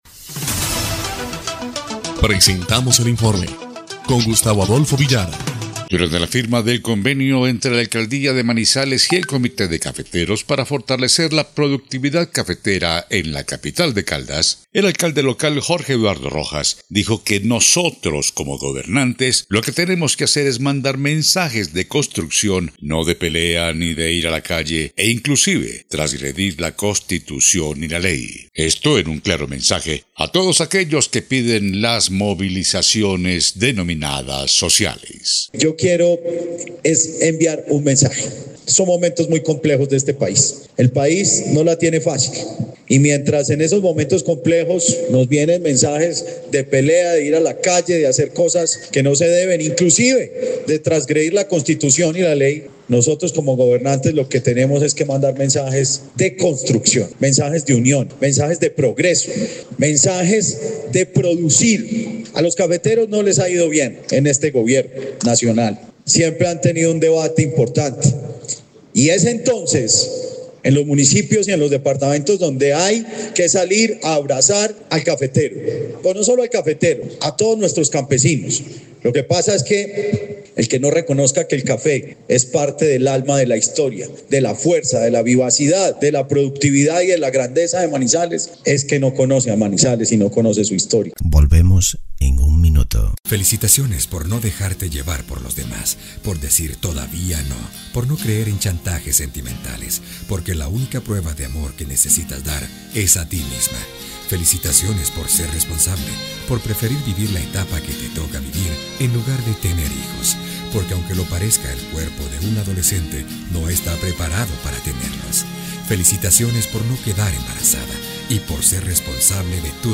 EL INFORME 4° Clip de Noticias del 5 de junio de 2025